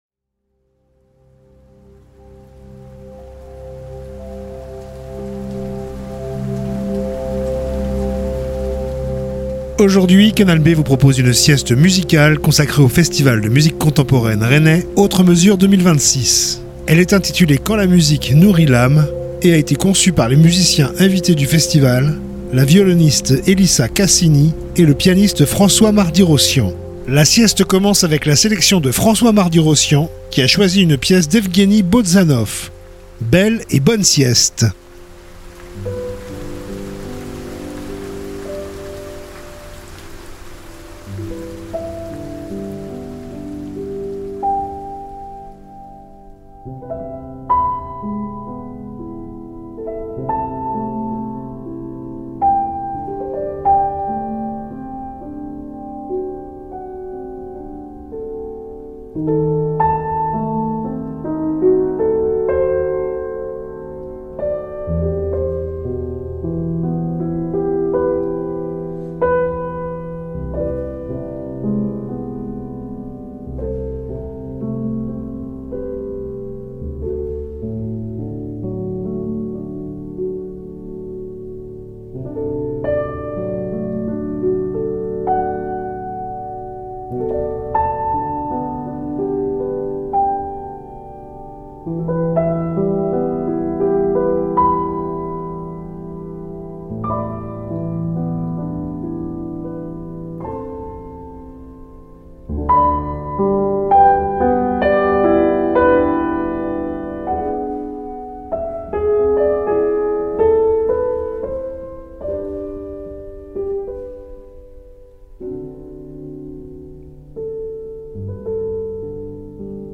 sieste musicale